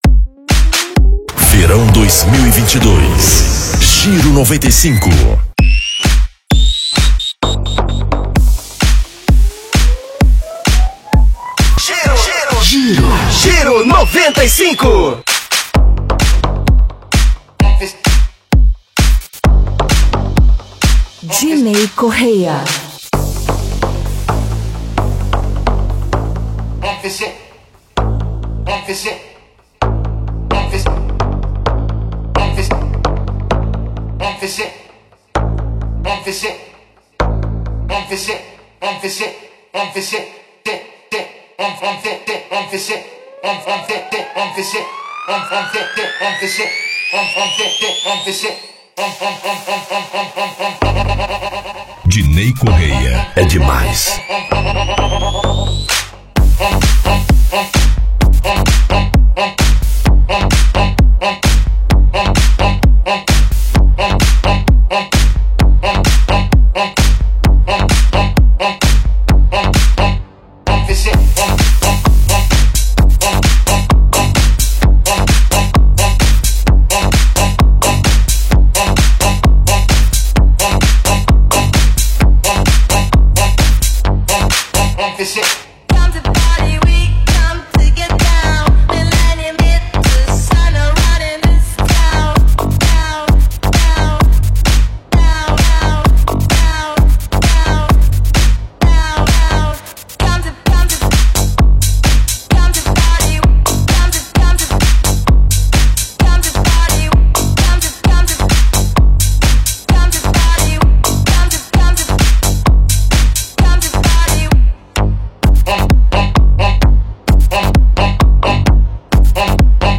mixado